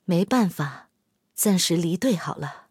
SU-122A中破修理语音.OGG